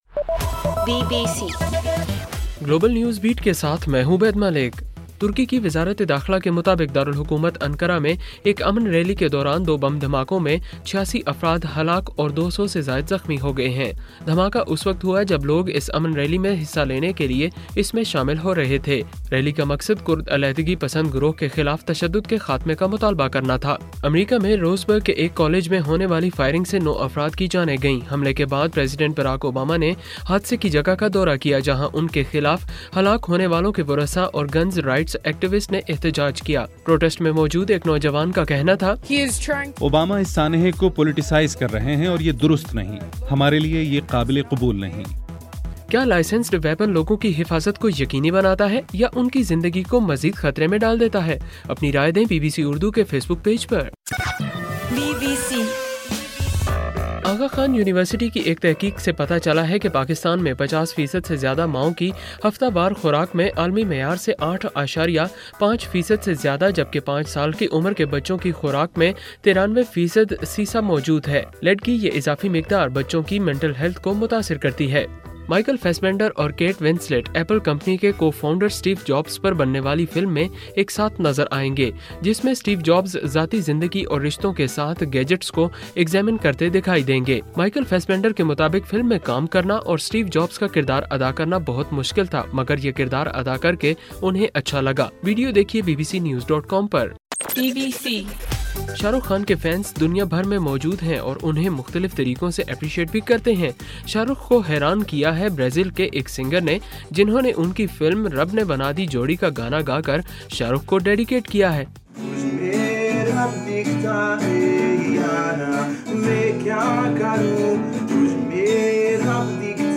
اکتوبر 10: رات 11 بجے کا گلوبل نیوز بیٹ بُلیٹن